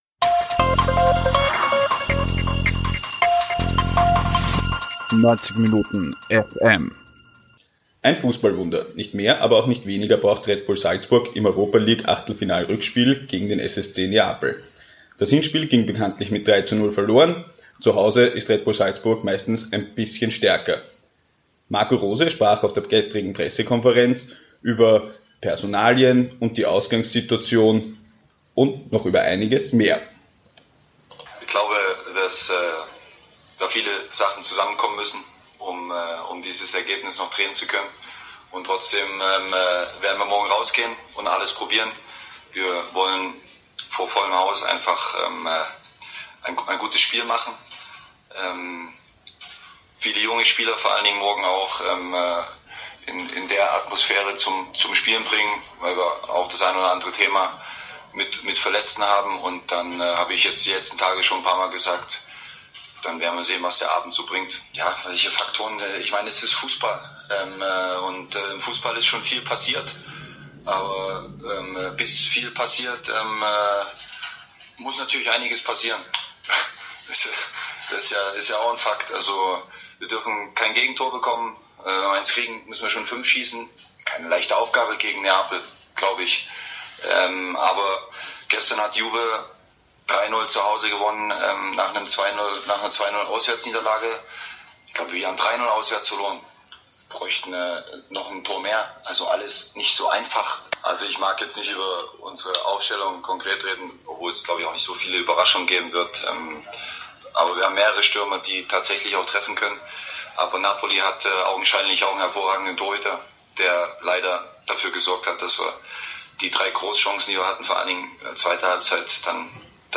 Salzburg-Pressekonferenz vor Neapel mit Marco Rose und André Ramalho